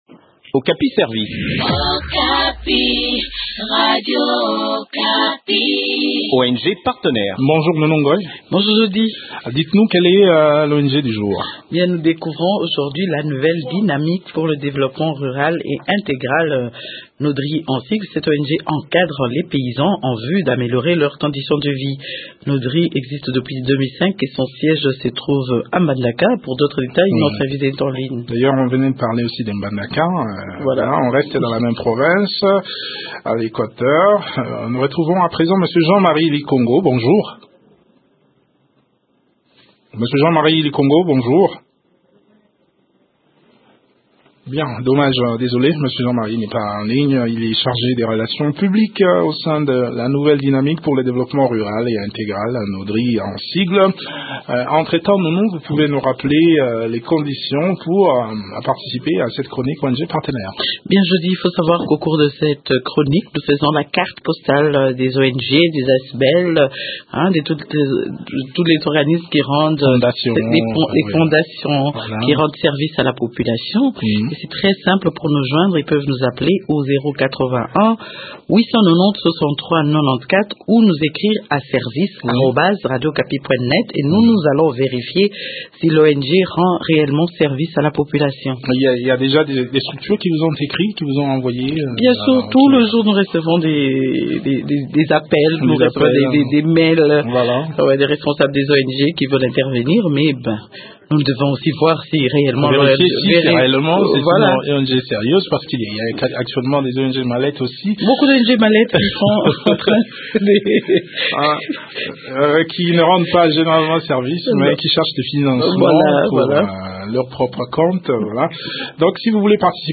Découvrons les activités de cette ONG dans cet entretien